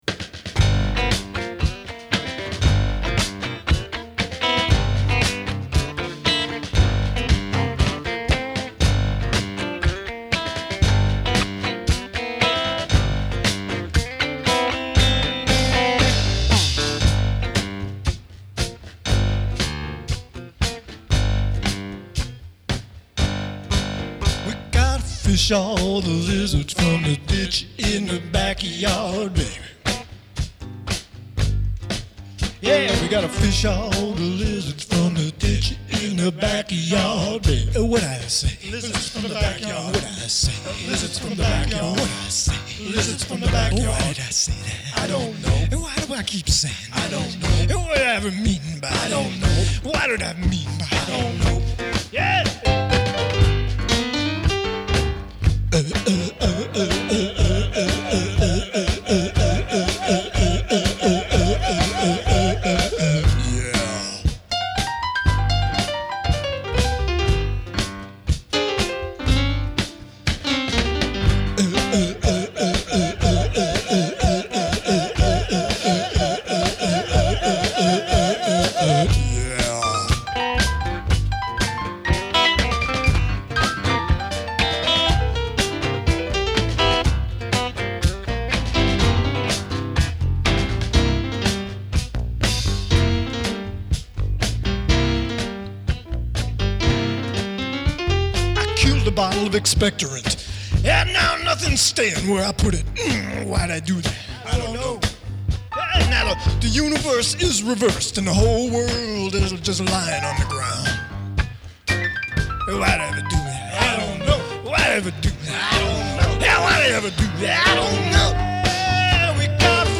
Lousy and sorrowful, but man, we was rockin’.